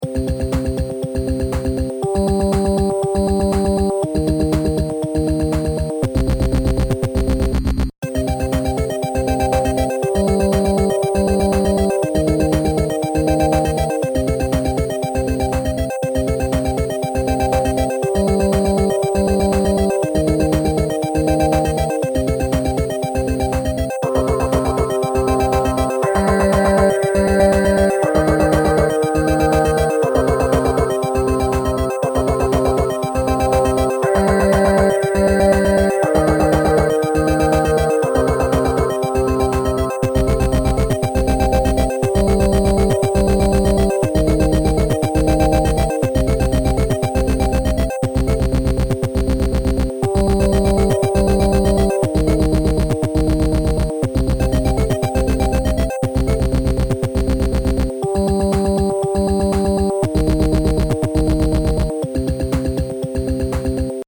Música electrónica
melodía rítmico sintetizador